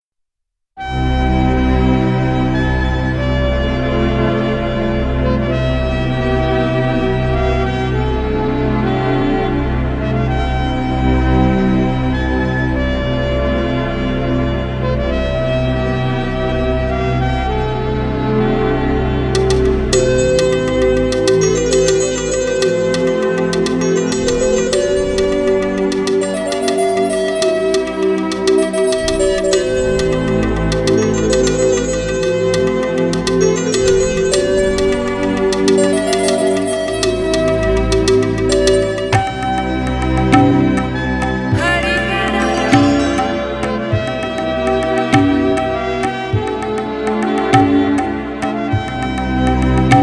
Stylowa wokaliza odkryje najgłębszą otchłań wyobraźni.